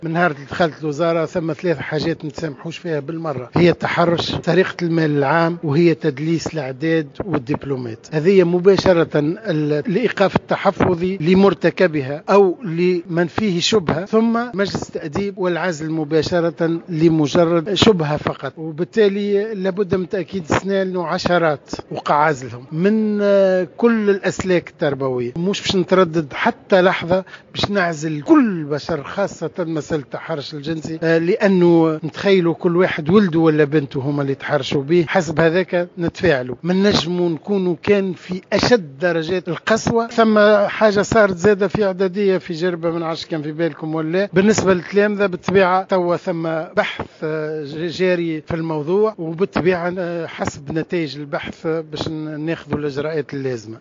أكد وزير التربية فتحي السلاوتي بخصوص حادثة التحرش الجنسي باحدى المدارس بولاية مدنين أن التعامل مع هذه الحالات صارم جدا و لا مجال للتسامح فيها .